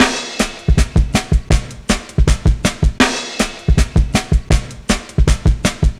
Index of /90_sSampleCDs/Zero-G - Total Drum Bass/Drumloops - 1/track 08 (160bpm)